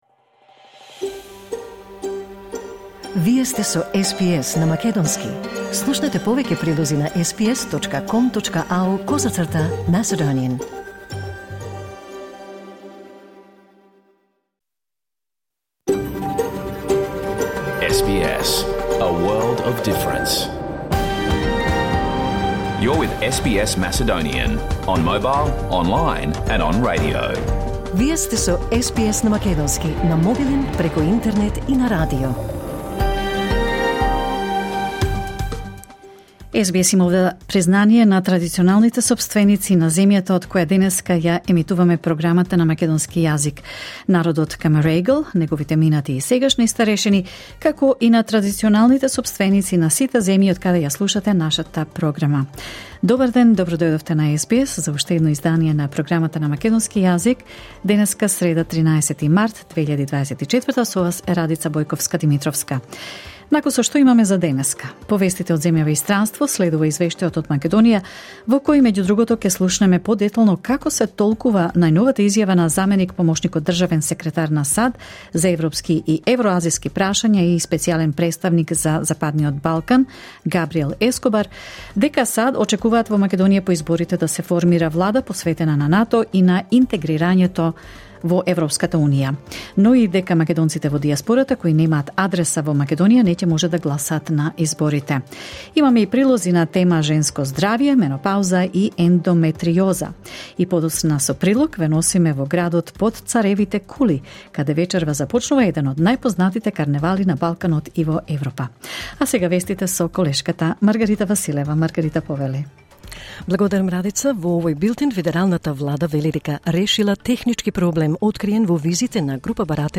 SBS Macedonian Program Live on Air 13 March 2024